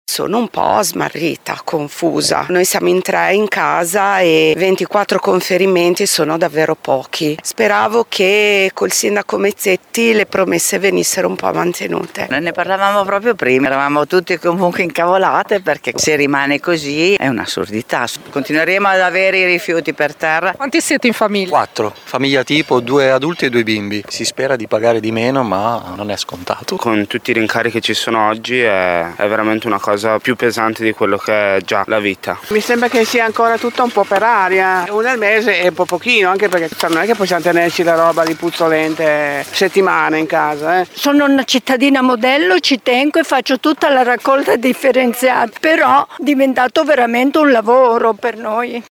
Sentiamo il parere di alcuni modenesi …